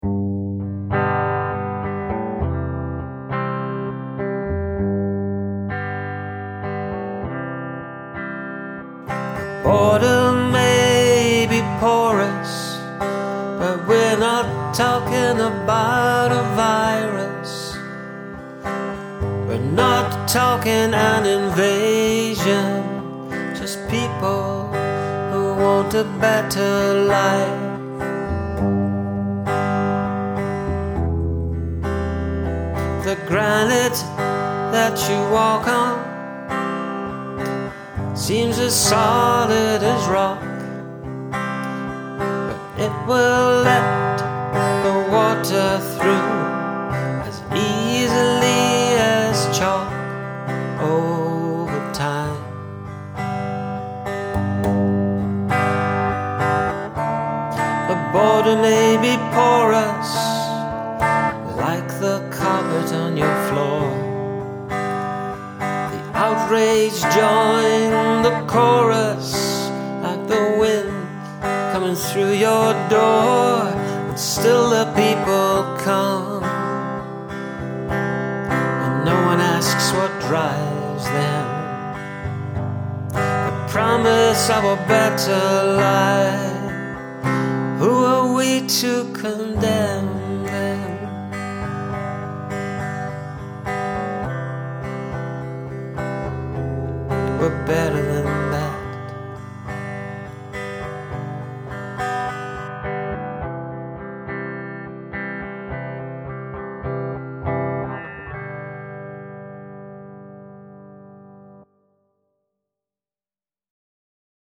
Same prompt, different take and a one-take at that.
And I like the way the melody flows.